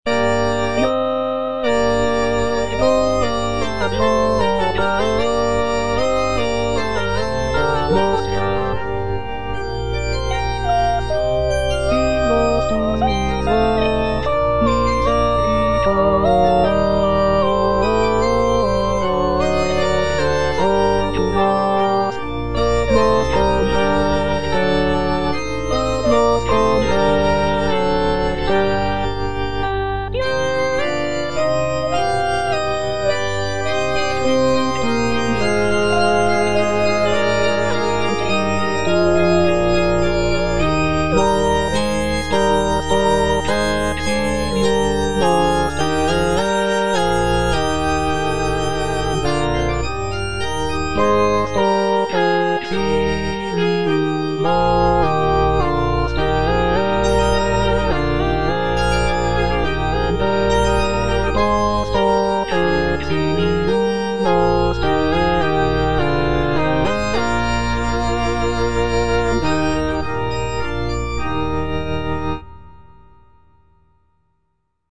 G.B. PERGOLESI - SALVE REGINA IN C MINOR Eja ergo advocata nostra (All voices) Ads stop: auto-stop
"Salve Regina in C minor" is a sacred choral work composed by Giovanni Battista Pergolesi in the early 18th century. It is a setting of the traditional Marian antiphon "Salve Regina" and is known for its poignant and expressive melodies. The piece is scored for soprano soloist, string orchestra, and continuo, and showcases Pergolesi's skill in writing for voice and orchestra.